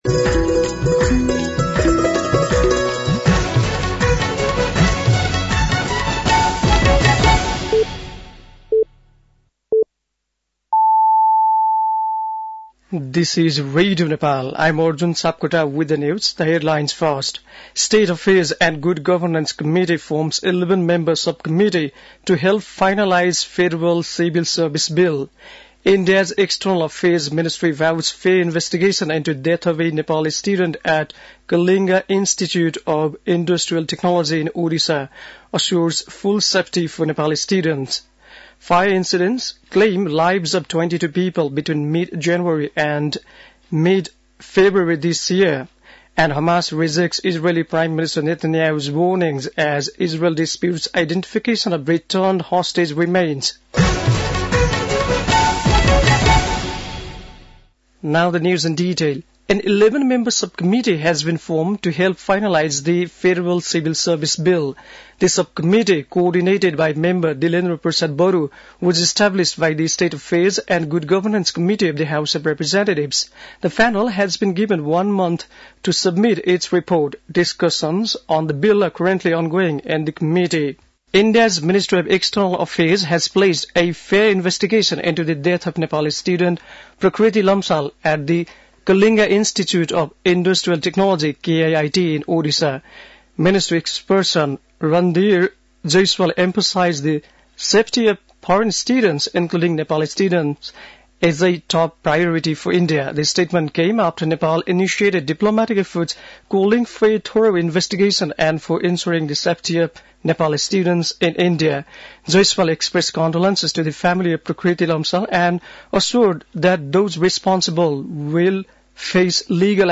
An online outlet of Nepal's national radio broadcaster
बेलुकी ८ बजेको अङ्ग्रेजी समाचार : १० फागुन , २०८१